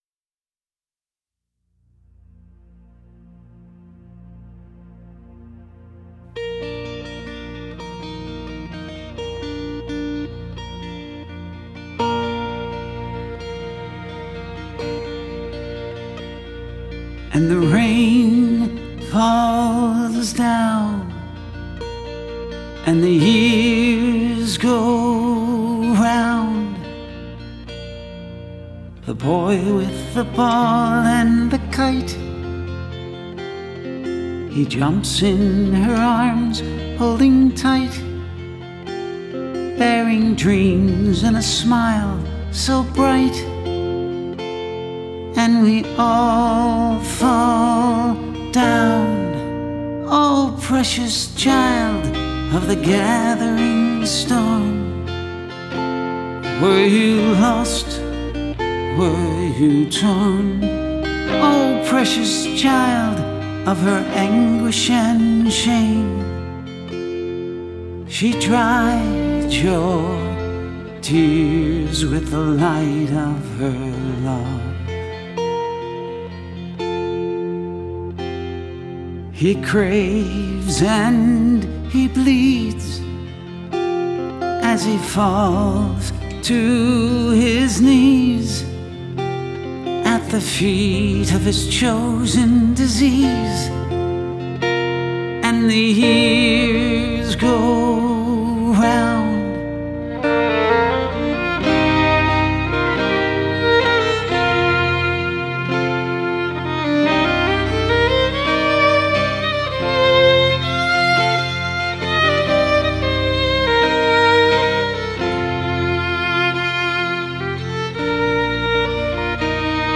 a singer/songwriter
Without a doubt, his words, music, and soulful voice will touch your heart, as they did mine.